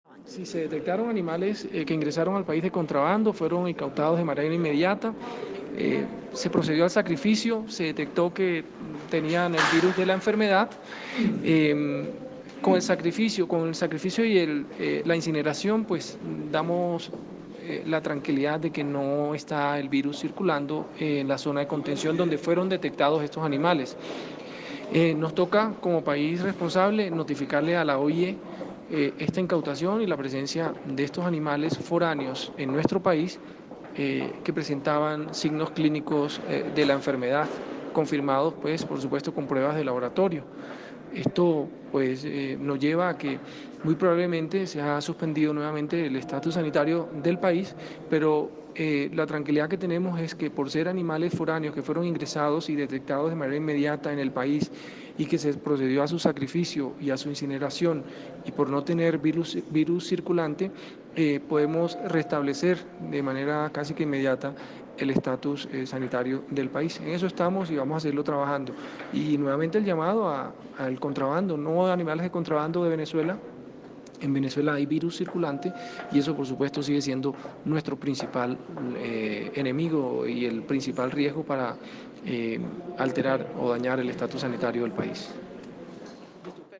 Rueda de prensa
• Escuche aquí la declaración del gerente general del ICA: